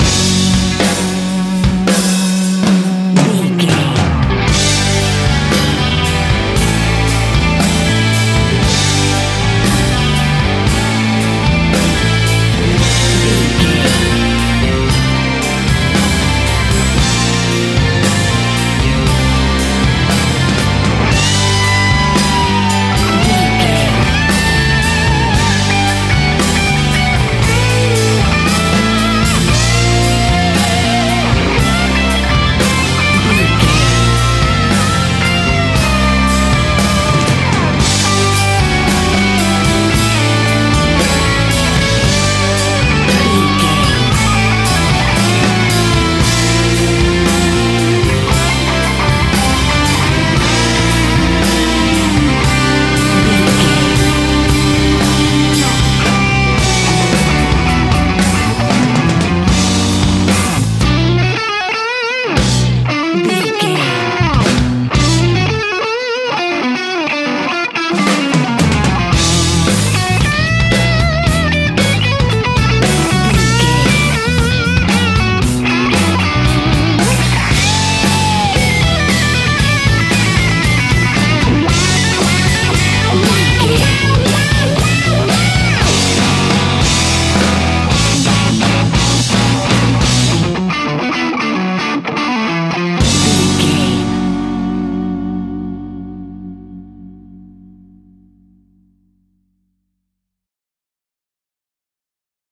Ionian/Major
D
drums
electric guitar
bass guitar
Prog Rock
hard rock
lead guitar
aggressive
energetic
intense
powerful
nu metal
alternative metal